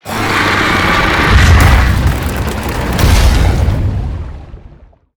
Sfx_creature_chelicerate_exoattack_exit_01.ogg